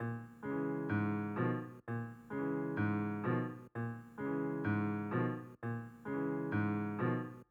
piano_128.wav